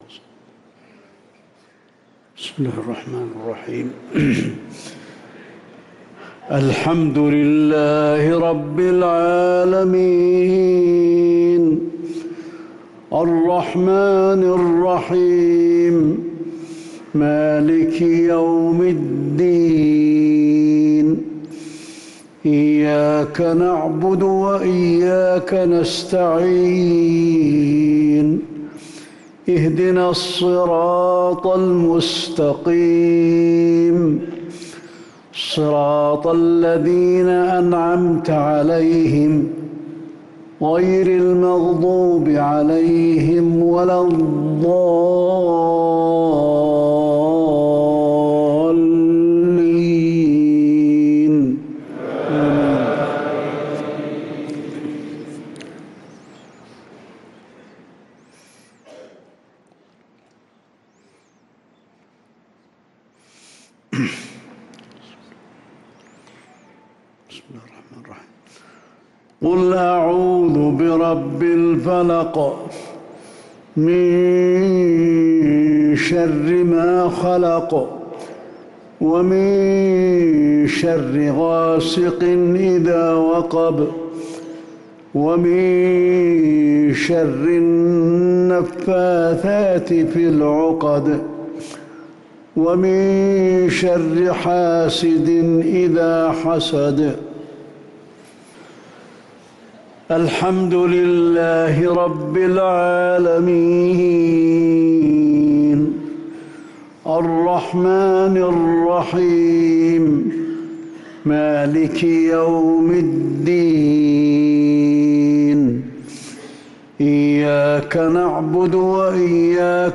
صلاة المغرب للقارئ علي الحذيفي 20 جمادي الأول 1445 هـ
تِلَاوَات الْحَرَمَيْن .